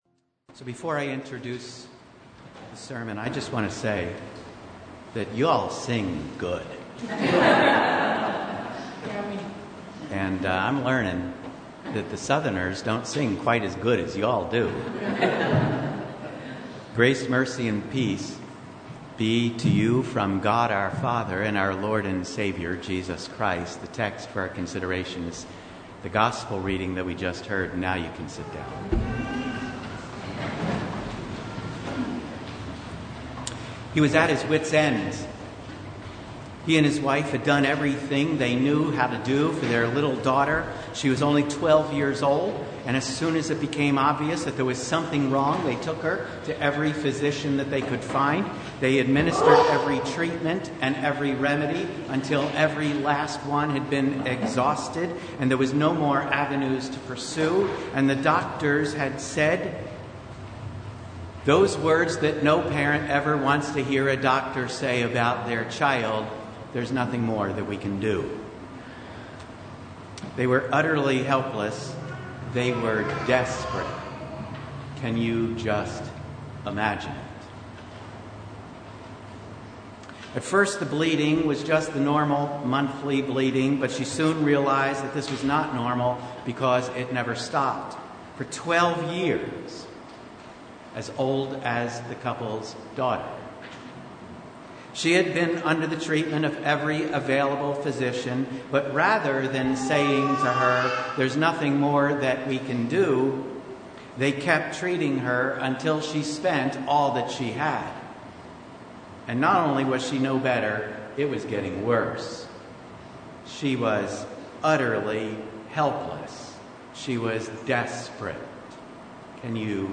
Download Files Notes Topics: Sermon Only « The First Sunday in Apostles’ Tide (audio recording) Don’t You Care If We Perish?